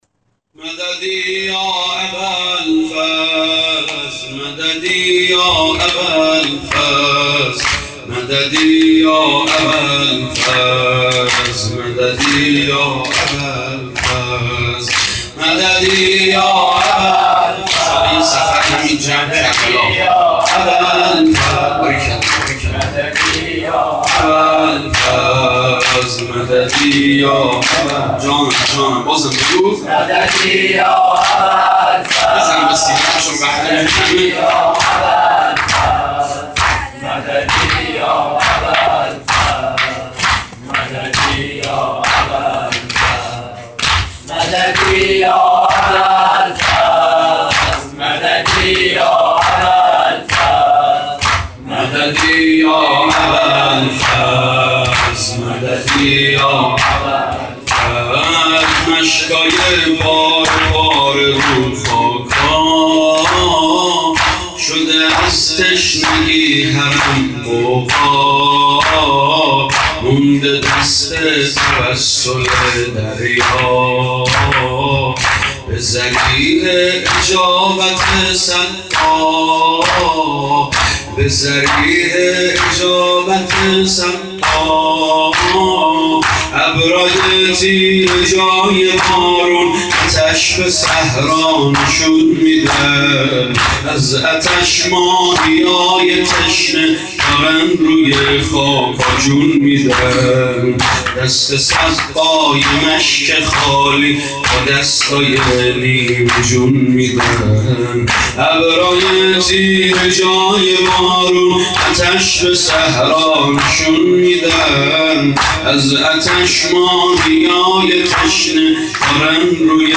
مناجات خوانی با نوای گرم